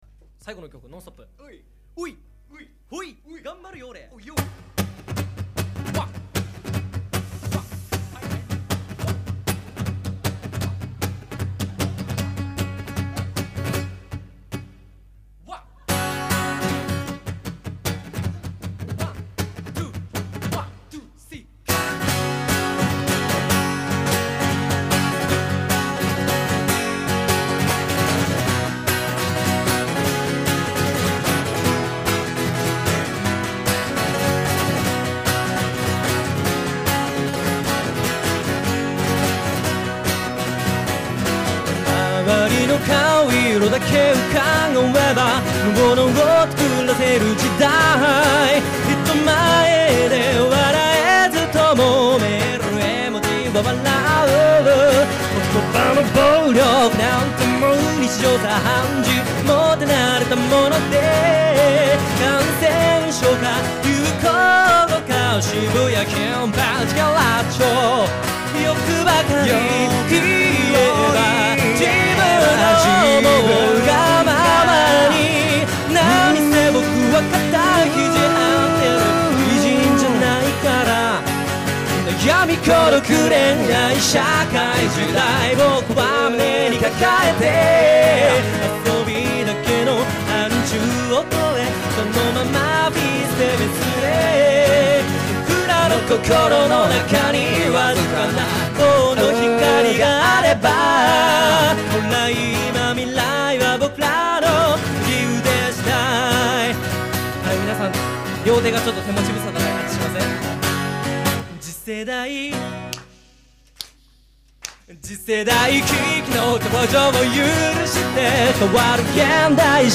基本的にレコーディング音源も５〜７年前の自主制作なので、音は悪いです。
ライブ音源なんかもっと悪いです。